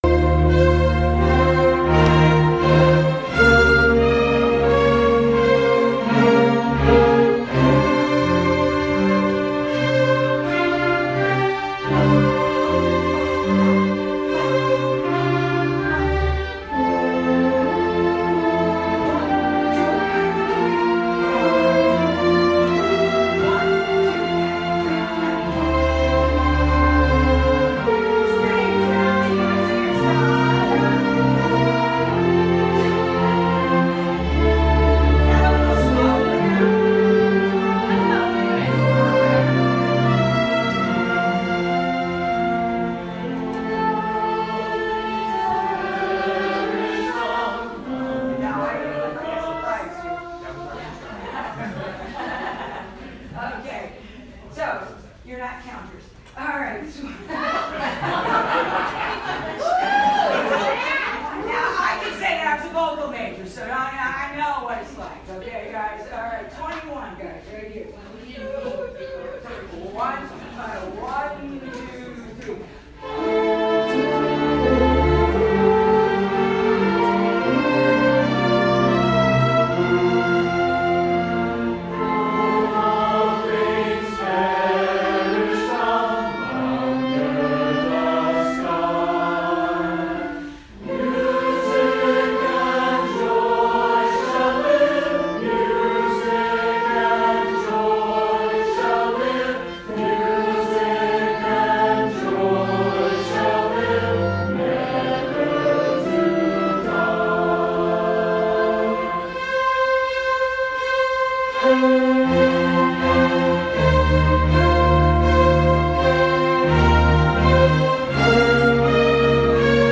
Wasn’t singing with the orchestra fun?
Music Shall Live – with orchestra